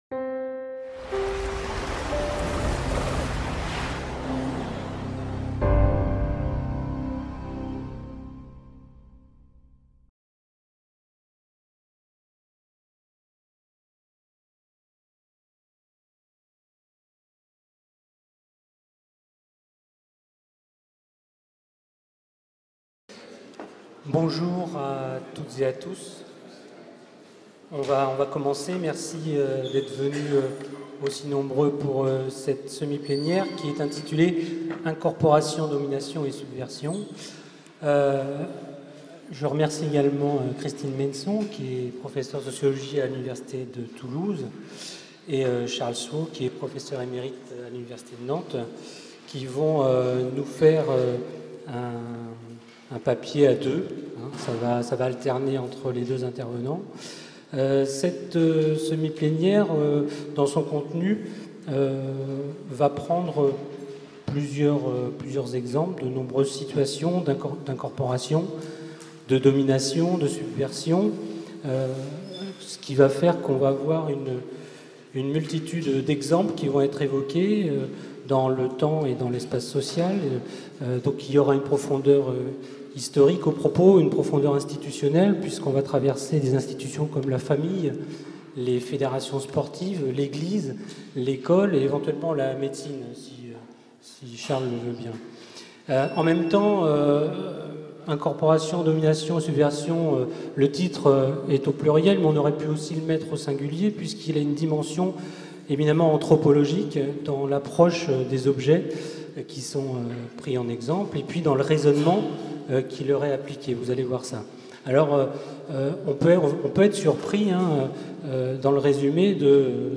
semi-plénière
L'UFR de Sociologie et le Centre Nantais de Sociologie (CENS) de l’Université de Nantes accueillaient du 2 au 5 septembre 2013 le 5e congrès international de l'association française de sociologie.